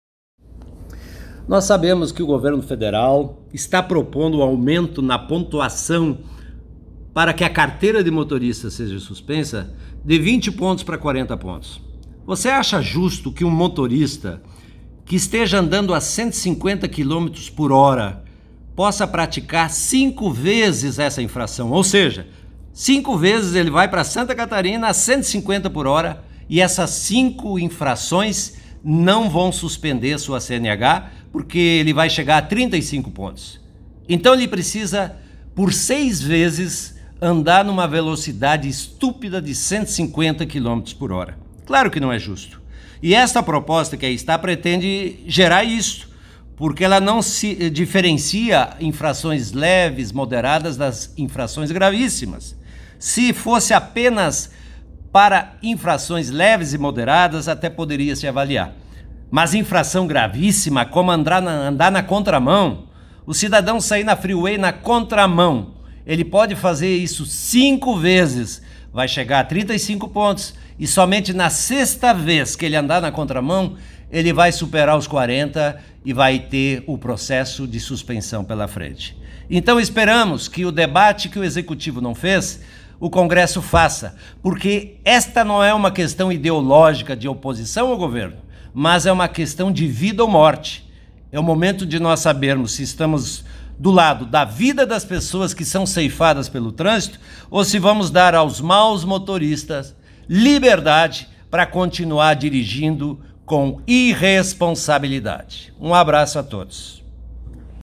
Diretor geral Enio Bacci fala sobre a proposta do governo federal para alteração do Código de Trânsito Brasileiro (CTB).